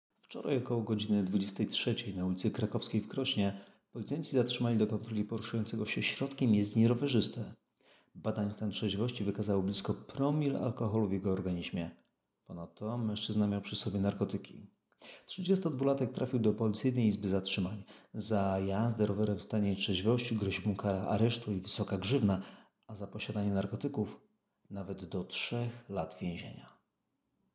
Nagranie audio Kontrola nietrzeźwego rowerzysty w Krośnie - mówi komisarz